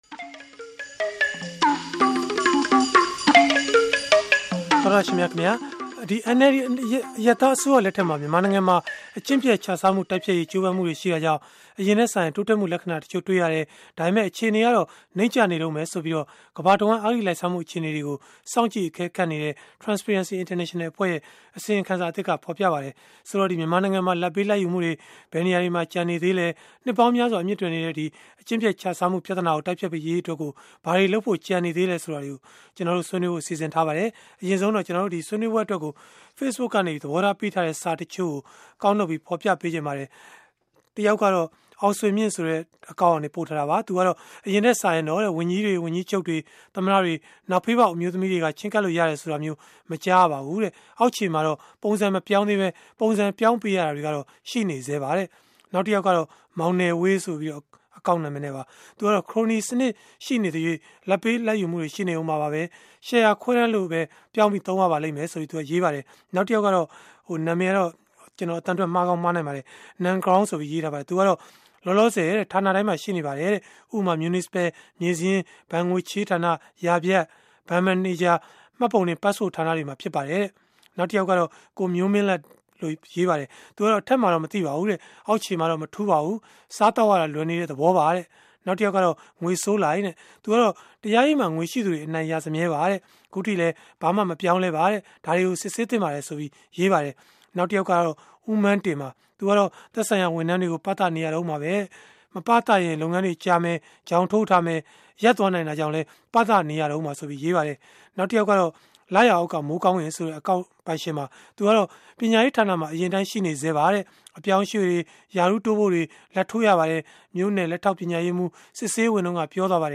စနေနေ့ည တိုက်ရိုက်လေလှိုင်း အစီအစဉ်မှာ